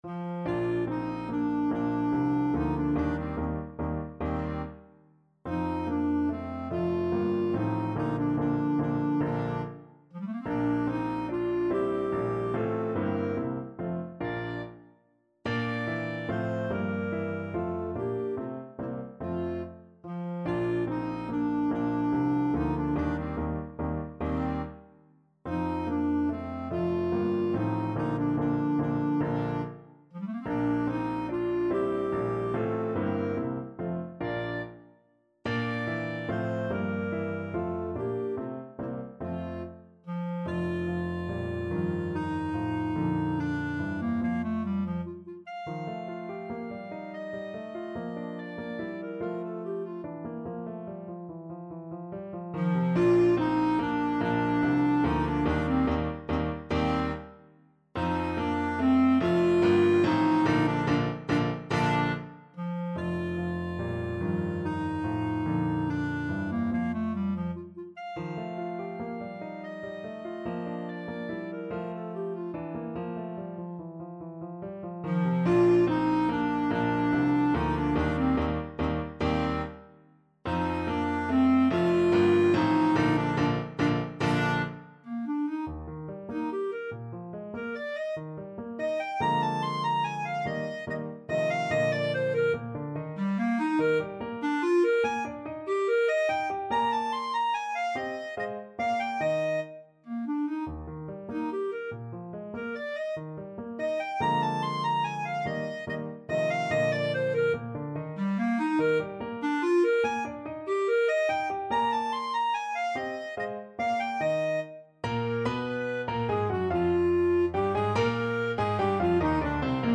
Bb major (Sounding Pitch) C major (Clarinet in Bb) (View more Bb major Music for Clarinet )
Moderato =c.144
3/4 (View more 3/4 Music)
Clarinet  (View more Intermediate Clarinet Music)
Classical (View more Classical Clarinet Music)